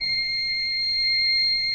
Index of /90_sSampleCDs/Propeller Island - Cathedral Organ/Partition K/ROHRFLOETE R